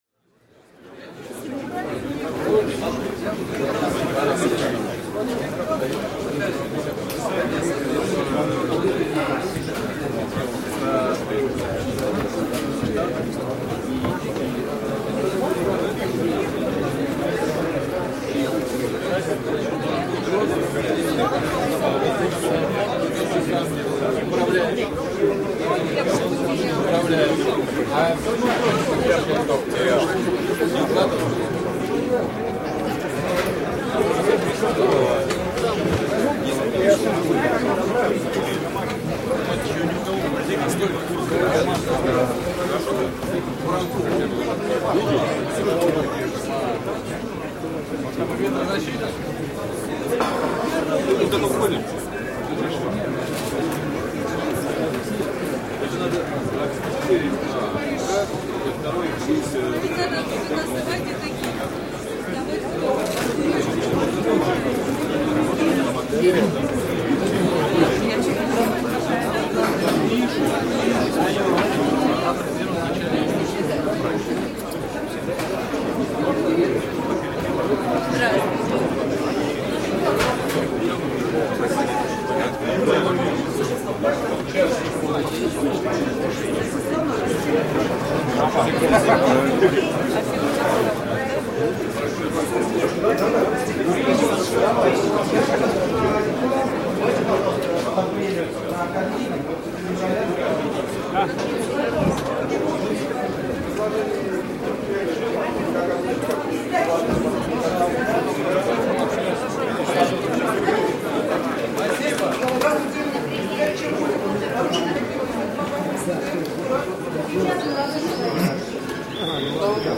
Здесь вы найдете фоновые шумы разговоров, звон бокалов, смех гостей и другие характерные звуки заведения.
Звуки московского бара из России